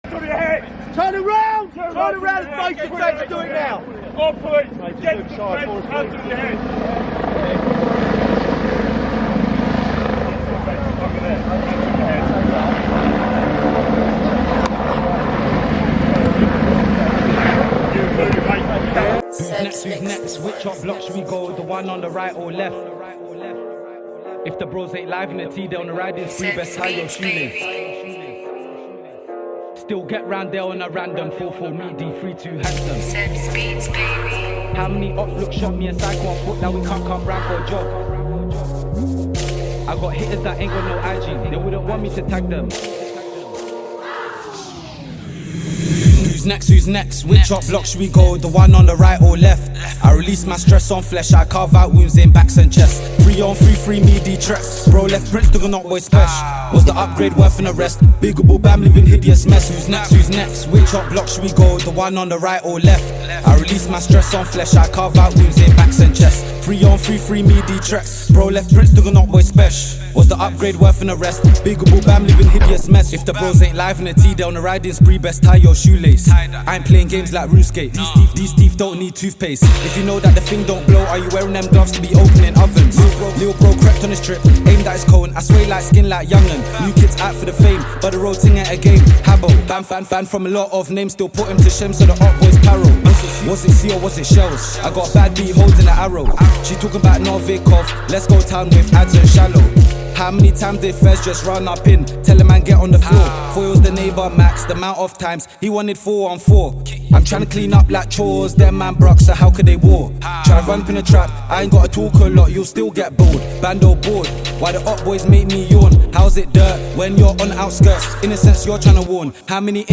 KDuun4lacNQ_drill-uk.mp3